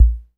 TR-55 KICK 1.wav